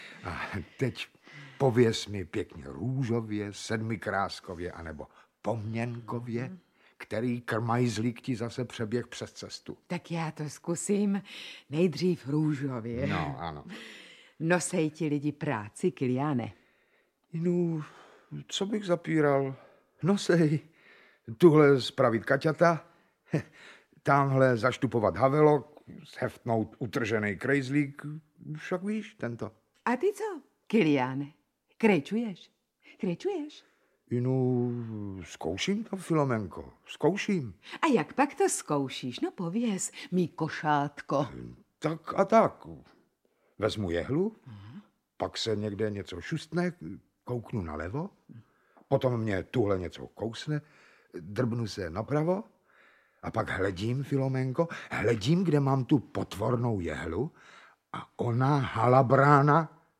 Audiobook
Read: Václav Neužil